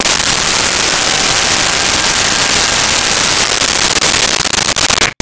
Blender On Chop
Blender on Chop.wav